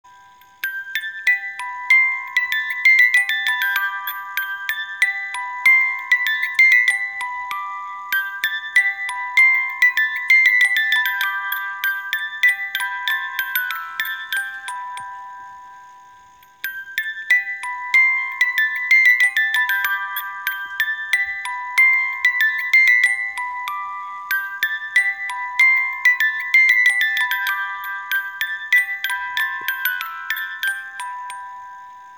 Es wird heute rund um den Erdball gesungen und ist Kinderlied und Volkslied zugleich. Die beruhigende, aber auch fröhliche Melodie unserer Spieluhr entstammt diesem Lied!